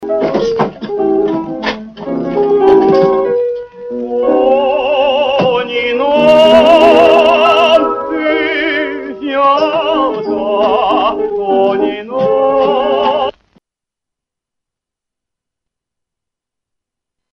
Песня звучит по радио.